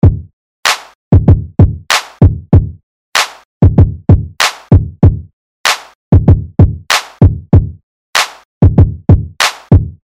an example of a somewhat complex pattern